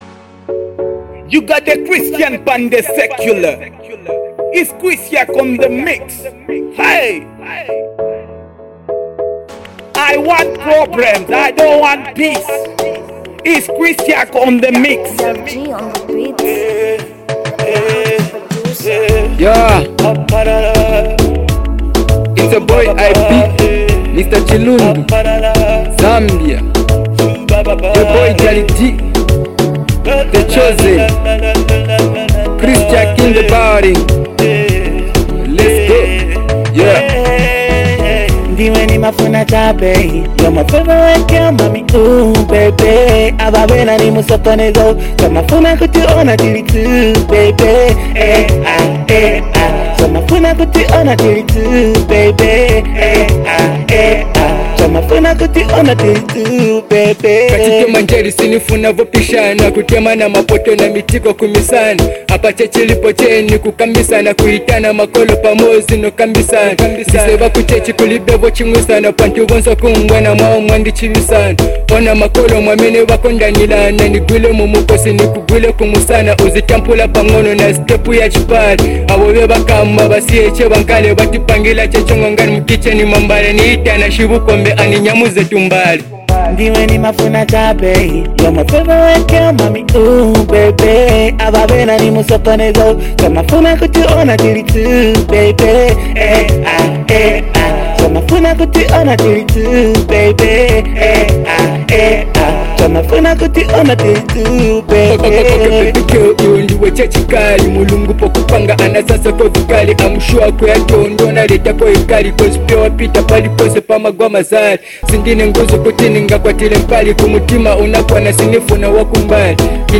merging two distinct styles into one harmonious hit.
Genre: R&B